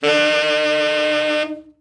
Index of /90_sSampleCDs/Giga Samples Collection/Sax/SAXOVERBLOWN
TENOR OB   9.wav